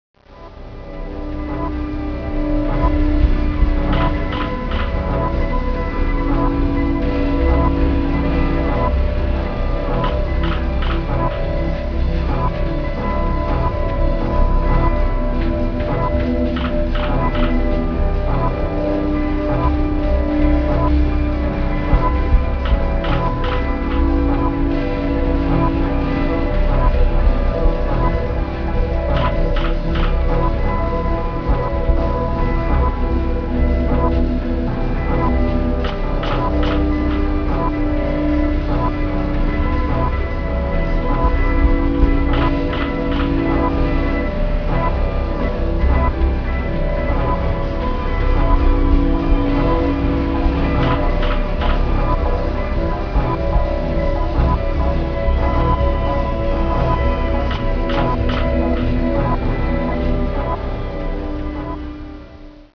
'techno'
gloriously crafted and oddly emotive
both hypnotic and startling